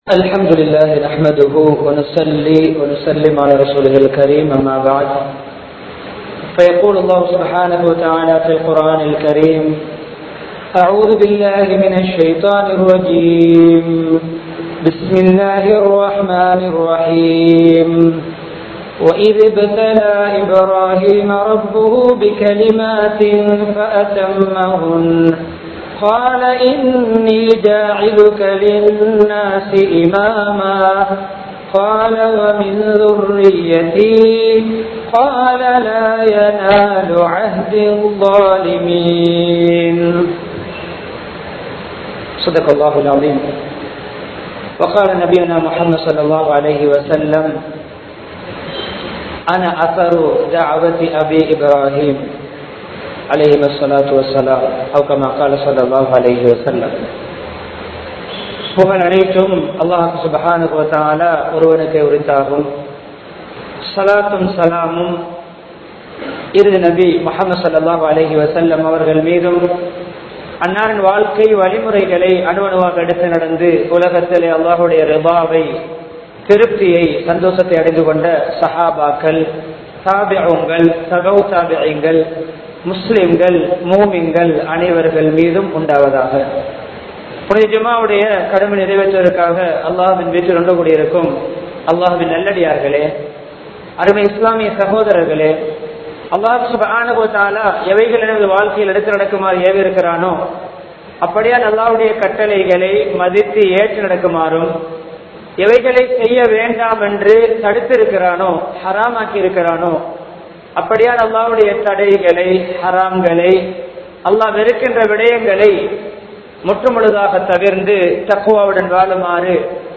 இப்ராஹிம்(அலை)அவர்களின் நற்பண்புகள் (Best Qualities of Ibrahim (Alai)) | Audio Bayans | All Ceylon Muslim Youth Community | Addalaichenai
Kandy, Ilukkuwaththa Jumua Masjidh